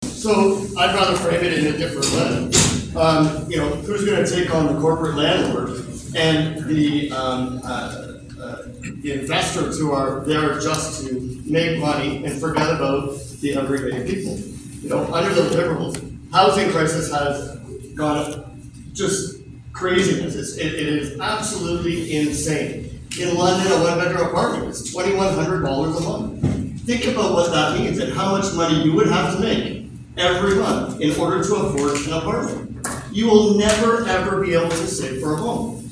The final all-candidate debate/meet and greet was held Wednesday at the Keystone Complex in Shedden with all four candidates participating, fielding pre-selected, ag-related questions.